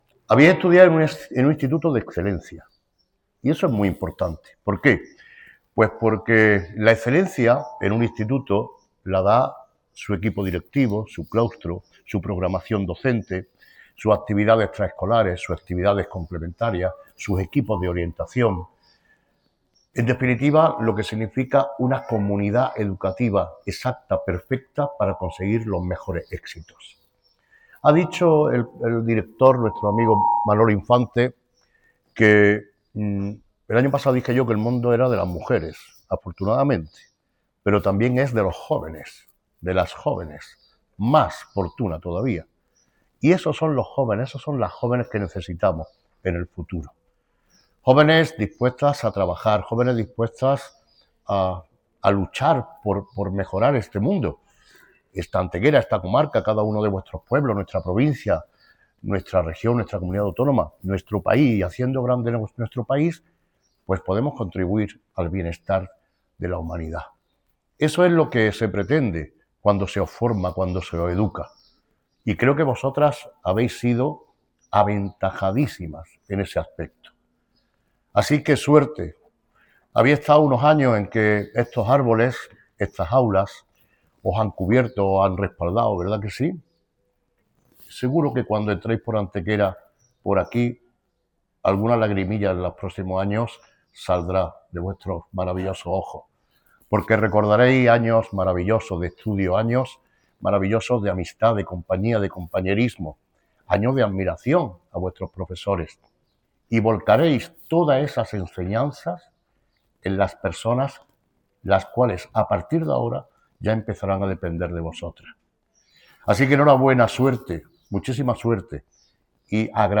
El alcalde Manolo Barón fue el encargado de cerrar el acto con un discurso cargado de emotividad y palabras de aliento para los jóvenes galardonados: “Habéis sido hoy absolutamente reconocidas en todo lo que significa vuestro esfuerzo y dedicación. Habéis estudiado en un instituto de excelencia y eso es muy importante”, destacaba el regidor, haciendo alusión al valor de la comunidad educativa del centro y al papel fundamental del profesorado, la dirección, el equipo de orientación y la programación docente.
Cortes de voz